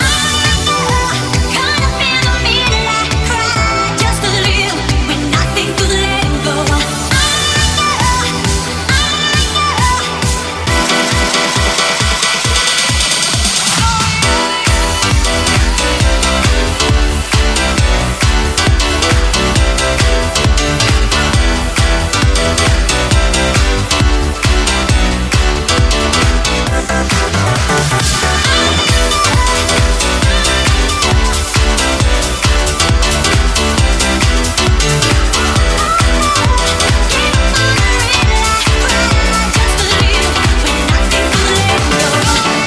Genere: deep house, tropical, house, club, edm, remix